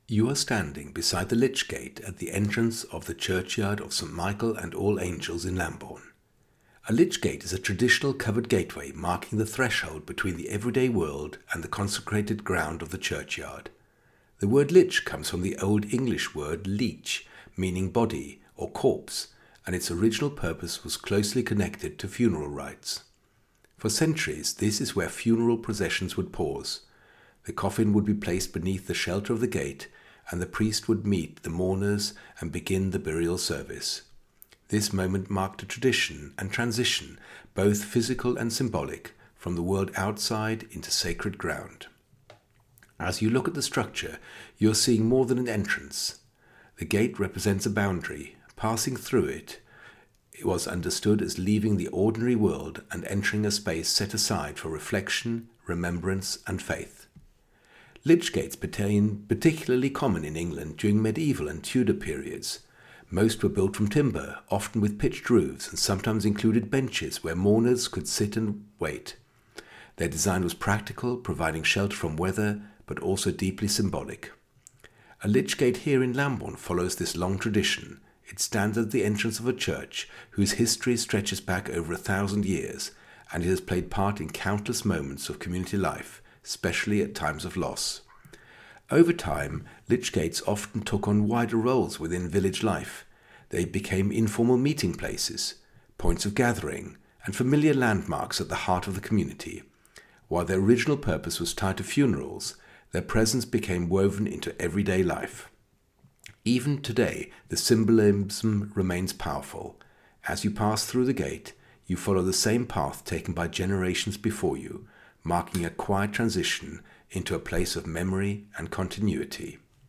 Scan the code and you are taken straight to a short, three minute audio guide.
Just a clear human voice explaining what you are looking at, as if a knowledgeable local guide were standing beside you.